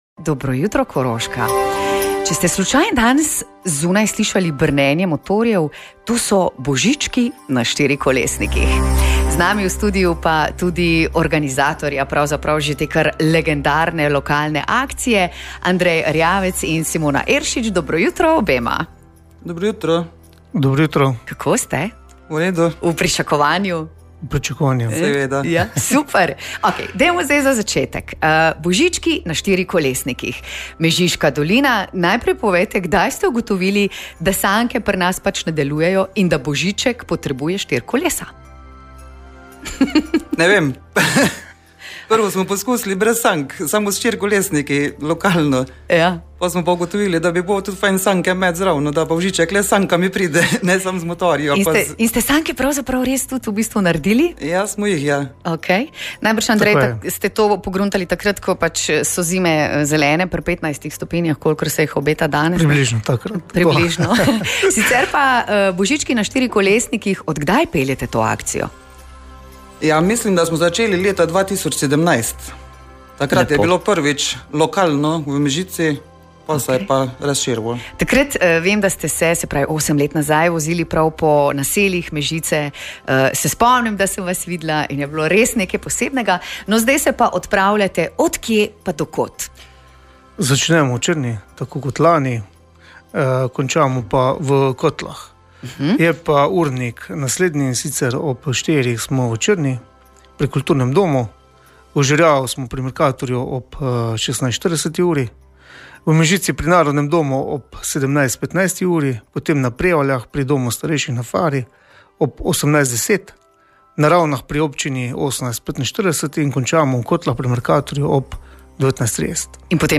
Točen urnik Božičkov ujamete v pogovoru.